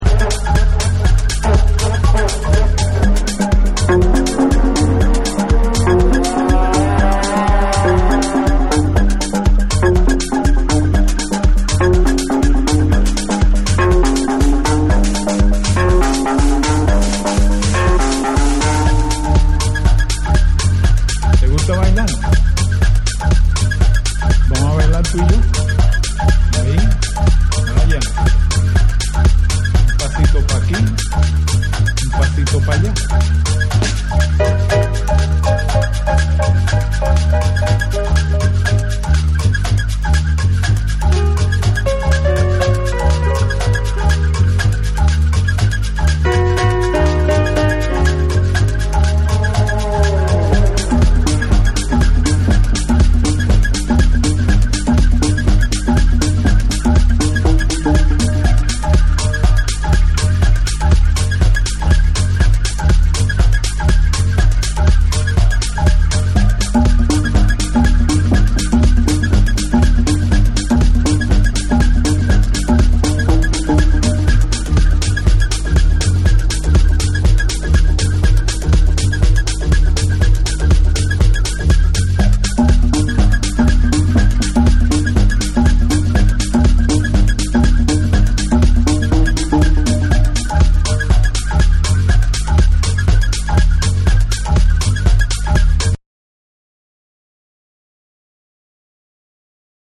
タイトル通りのアフリカン・テック・ハウス！
TECHNO & HOUSE / ORGANIC GROOVE